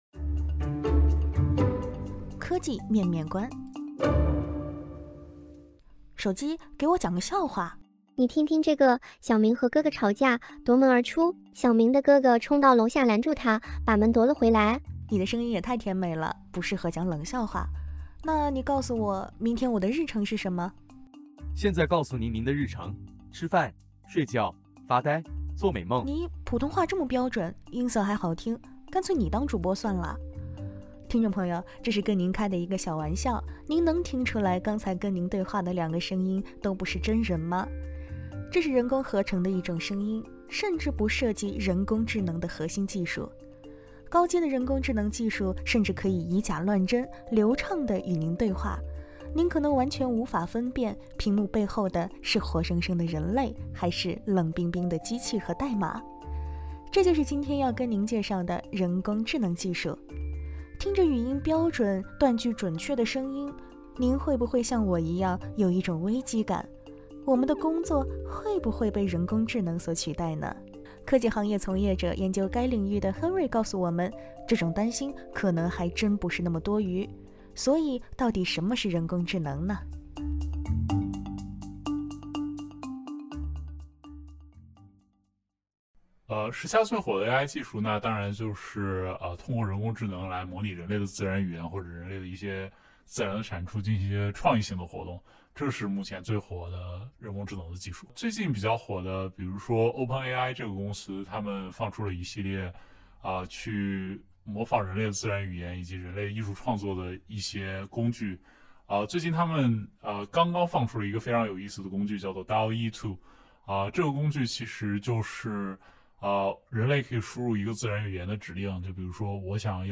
在本集Podcast中，您首先听到的是人工合成的一种声音，甚至不涉及人工职能的核心技术。